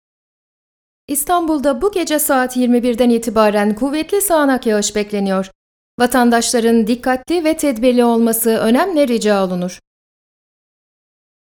I have a warm, pleasant, informative and uplifting voice. I have both young and adult voice. I have an informative voice.
I have my own professional studio.